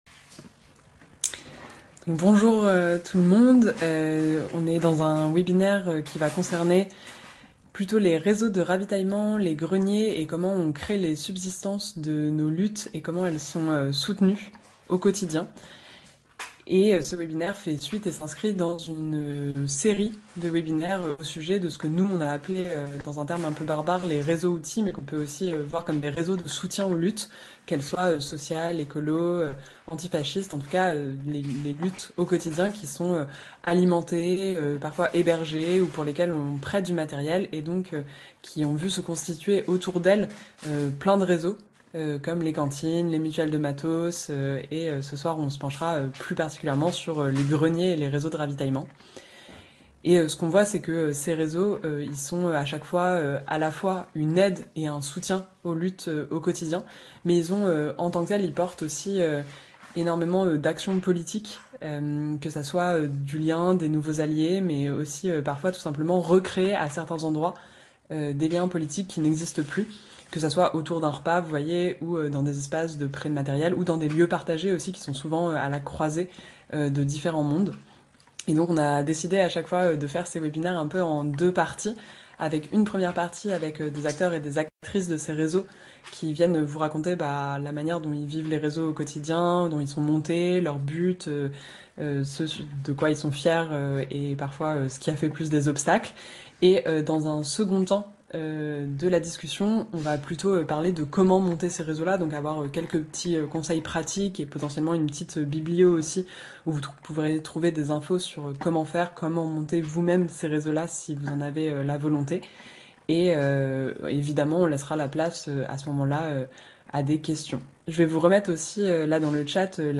Webinaire - Greniers et réseaux de ravitaillement
Jeudi 22 janvier à 18h30, des personnes du réseau de ravitaillement de Rennes, du syndicat Sud PTT, des caves des Soulèvement et de divers greniers viendront nous partager leurs expériences, leur vision politique ainsi que leurs conseils pour organiser et déployer des greniers et réseaux de ravitaillement !